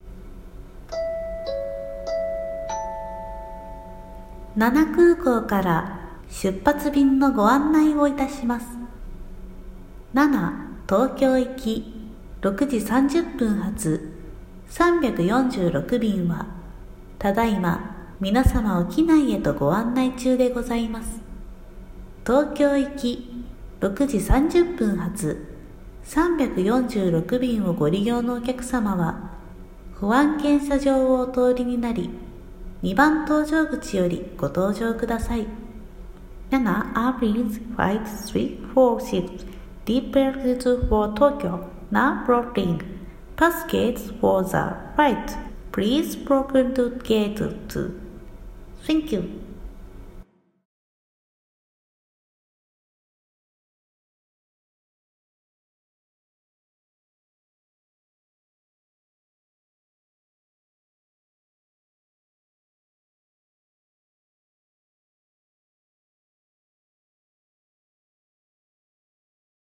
【声劇】空港アナウンス